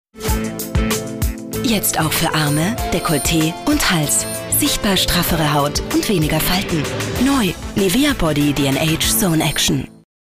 STIMMLAGE: kräftig, voll, warm, markant, sinnlich, zwischen 25-45 J. einsetzbar.
Sprechprobe: Werbung (Muttersprache):
female voice over talent german.